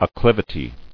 [ac·cliv·i·ty]